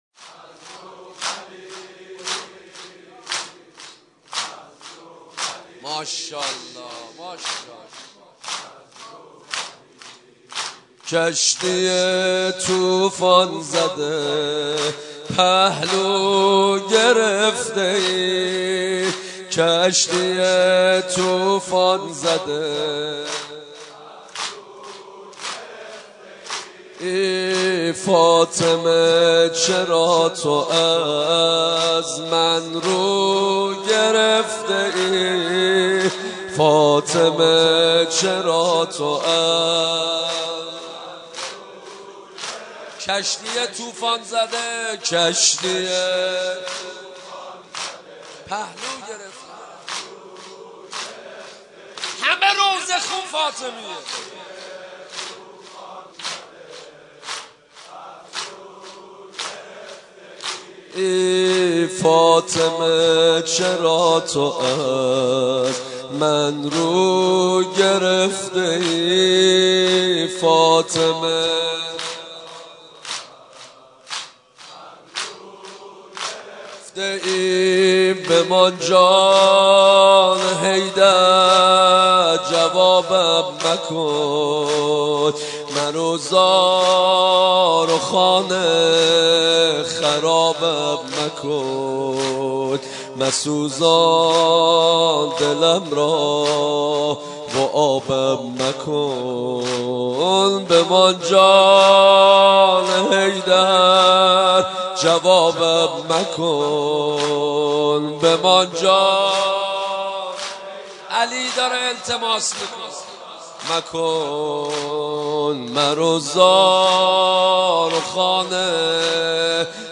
سینه زنی فاطمیه 1395
[سینه زنی زمینه]